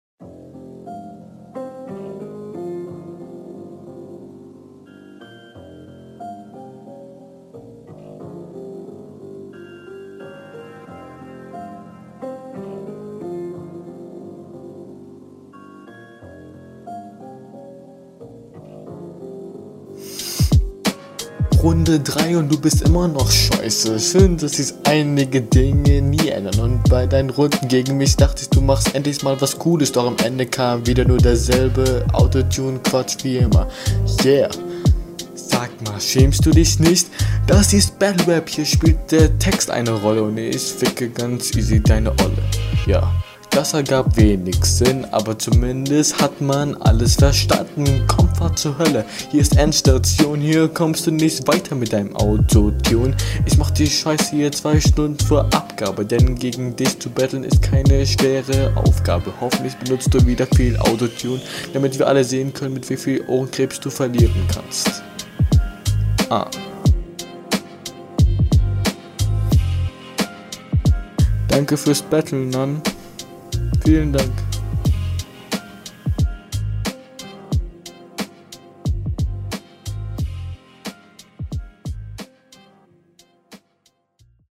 Uh, hier ist die Audio besser als in den Runden davor, find ich gut.
Beat cool.